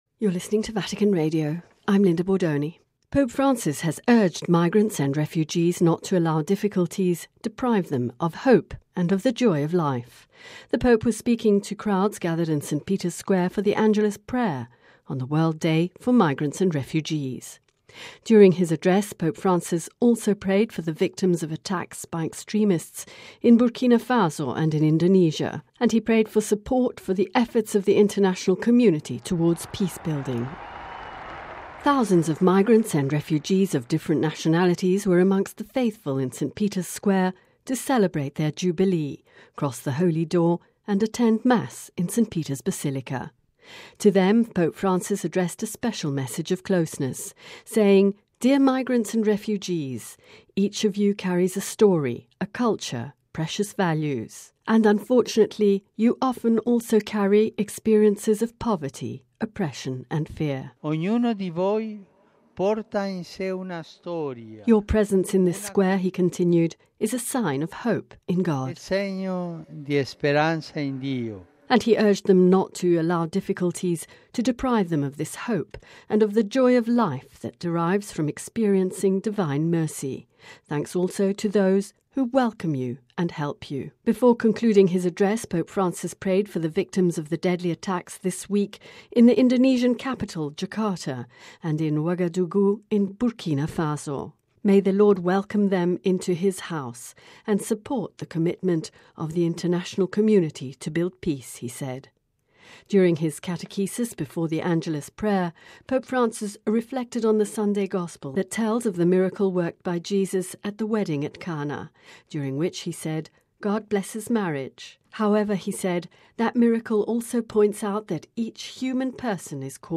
The Pope was speaking to crowds gathered in St. Peter’s Square for the Angelus prayer on the World Day for Migrants and Refugees.